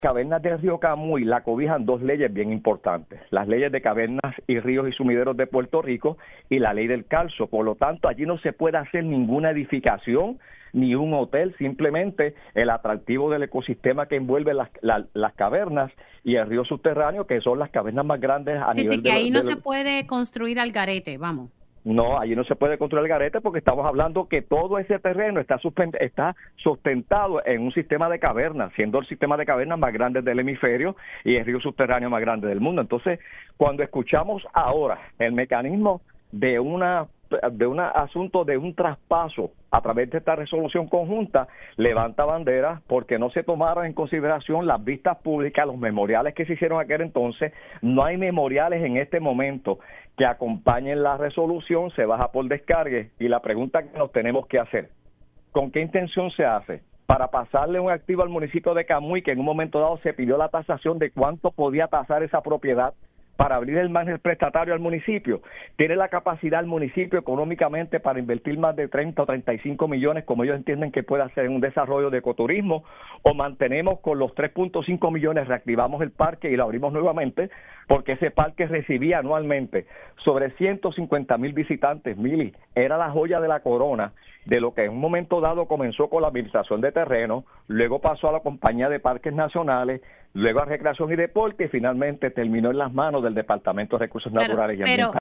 Además, el exsenador Ramón Ruiz Nieves señaló que la Resolución levanta varias banderas por el proceso que no se realizó antes de bajar la medida por descargue.